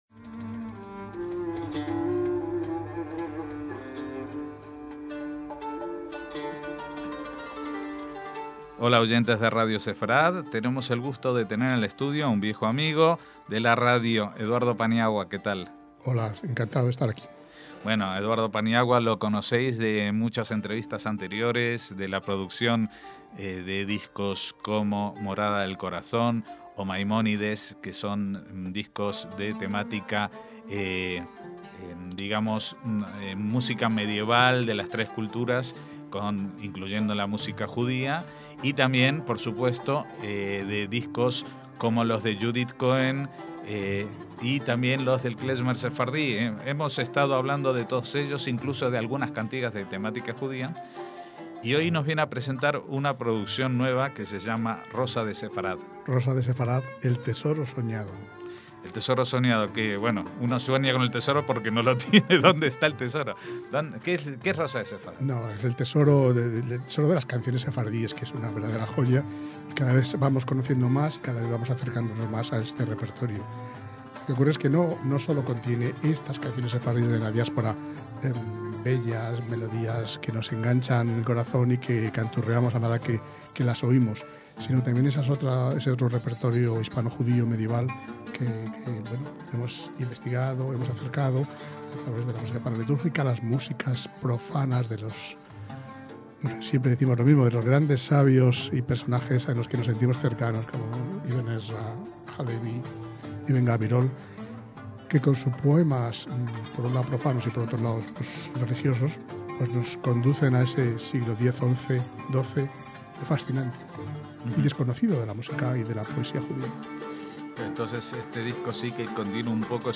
KOLOT: VOCES DE AYER Y DE HOY - En octubre de 2013 entrevistábamos al multiinstrumentista y productor musical Eduardo Paniagua acerca del lanzamiento de Rosa de Sefarad, un álbum dedicado a las piezas de la tradición judeoespañola de los sefardíes.